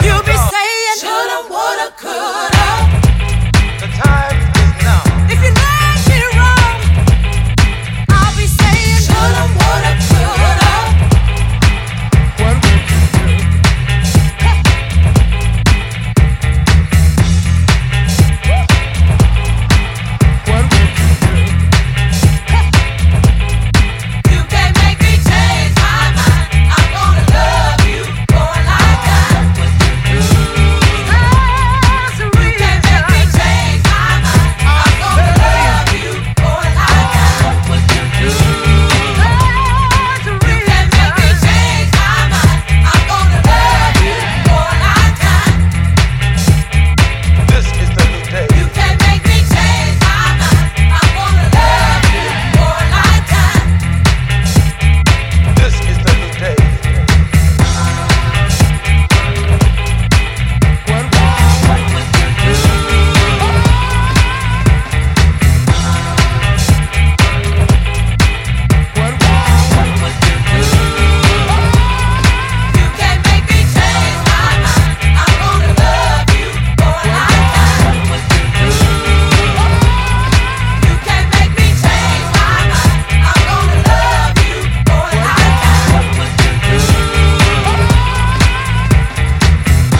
ジャンル(スタイル) DEEP HOUSE / SOULFUL HOUSE / HOUSE